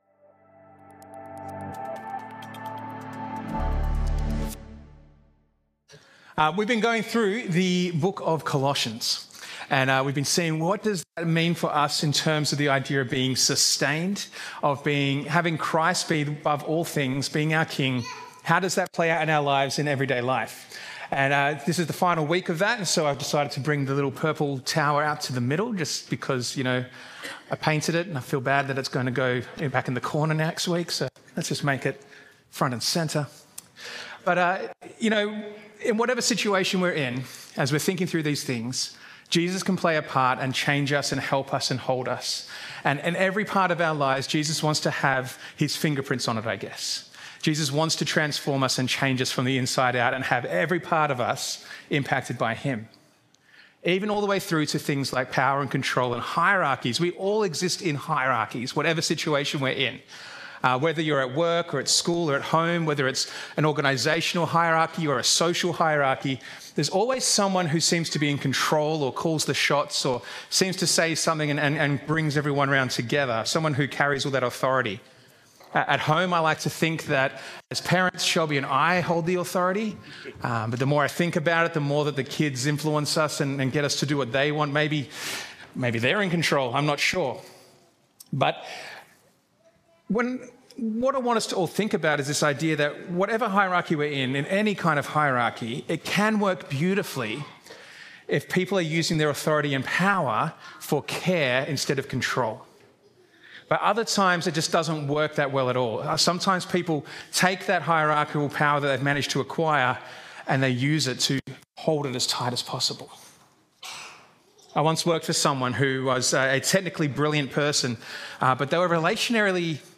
Sermons - Como Baptist Church